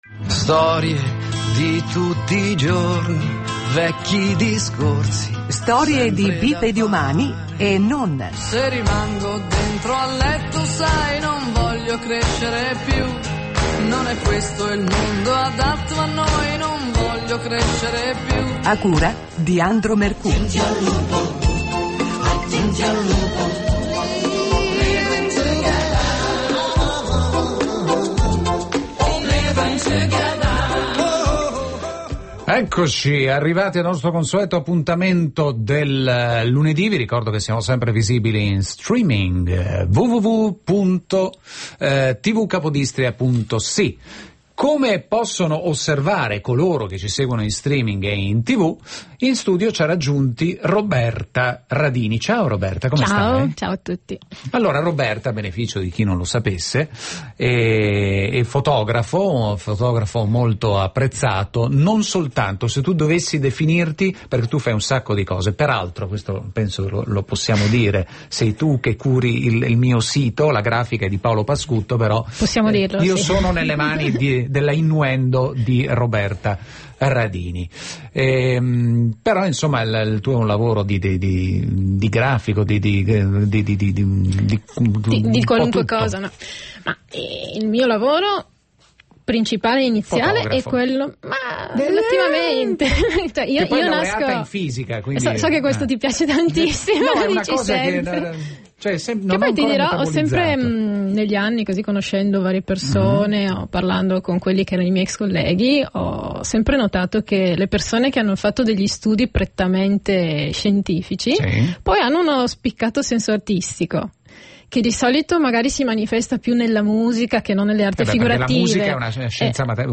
Intervista Radio Capodistria